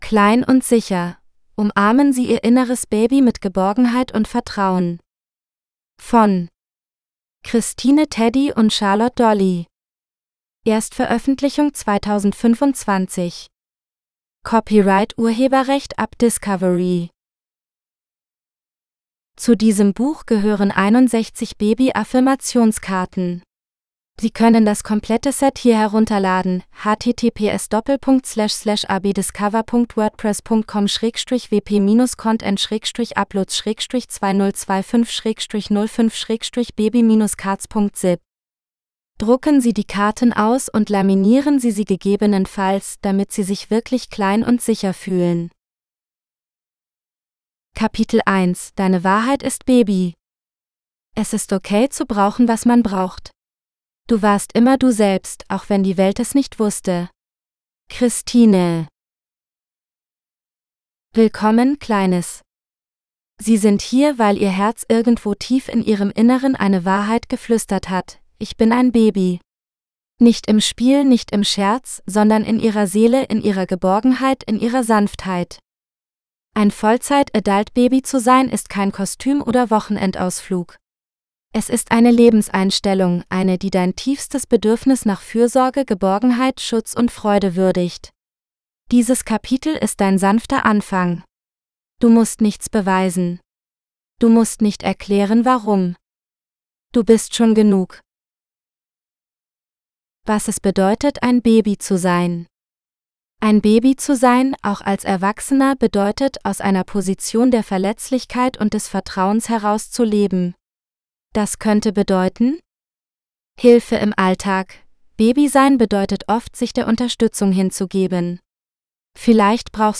Little and Safe GERMAN (AUDIOBOOK – female): $US6.50
little-and-safe-german-preview.mp3